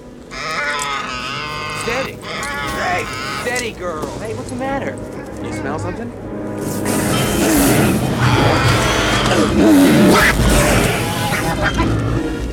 ―Luke Skywalker calms his nervous tauntaun before a wampa attack — (audio)
Wampa-Attack.ogg